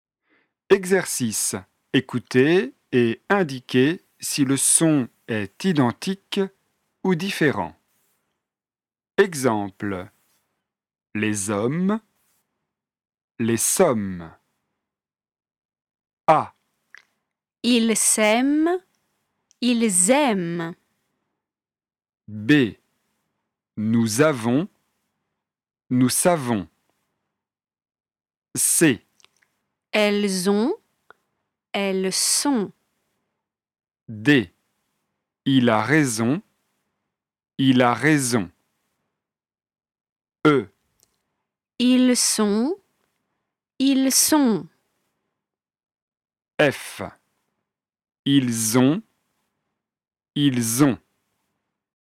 • exercices de discrimination
Opposition de la consonne [s]  comme dans « essentiel » et [z] comme dans « Zazie zozote ».